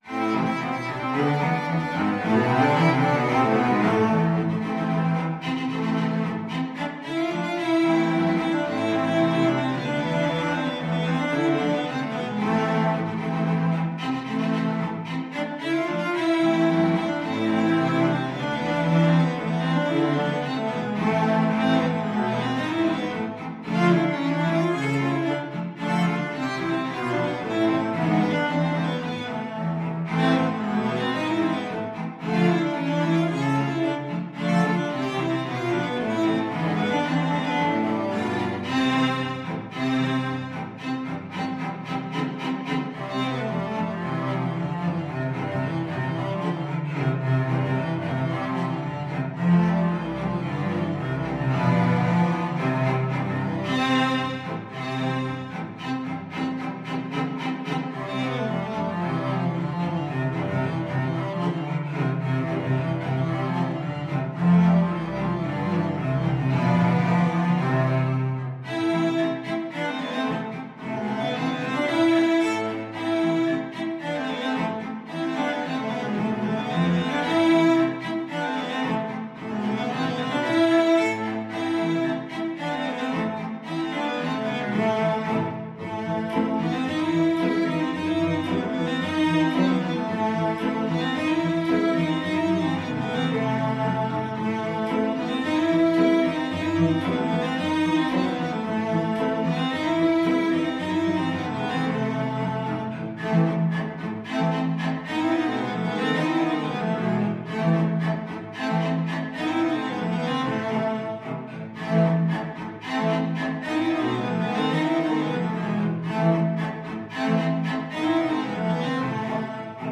Free Sheet music for Cello Ensemble
Cello 1 (Solo)Cello 2Cello 3Cello 4Cello 5
2/4 (View more 2/4 Music)
A minor (Sounding Pitch) (View more A minor Music for Cello Ensemble )
Traditional (View more Traditional Cello Ensemble Music)
world (View more world Cello Ensemble Music)